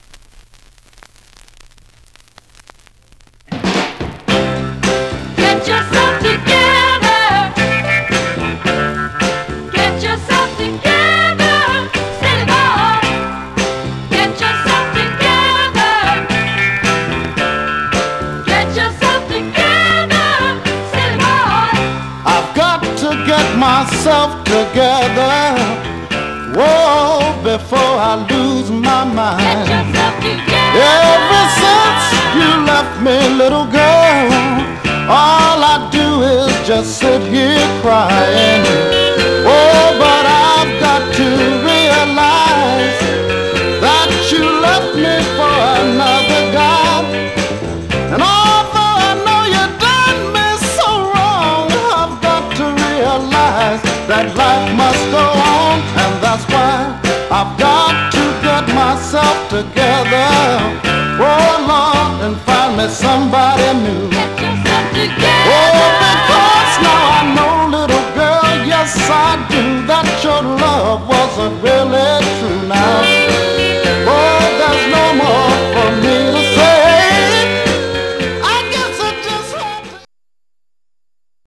VG+ DJ RARE NORTHERN